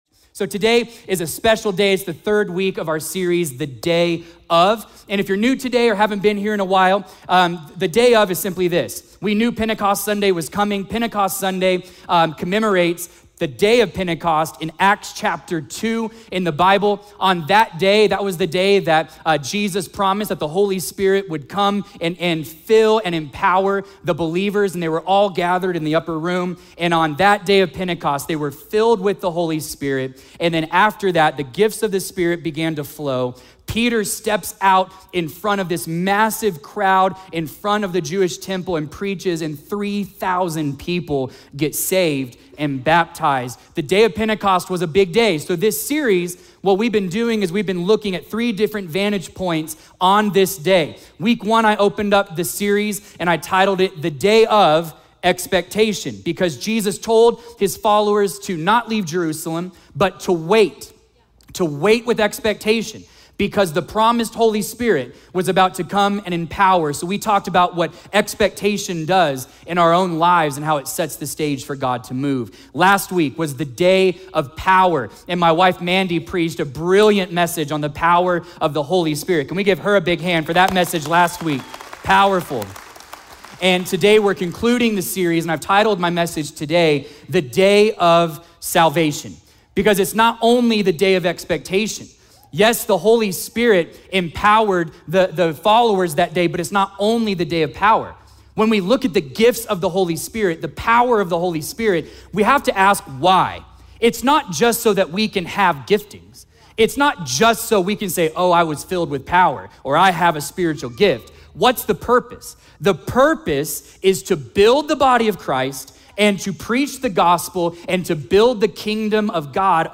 A message from the series "The Day of." Scripture: Acts 1–2 | The Day of Pentecost What if waiting wasn’t wasted time—but preparation for a divine move?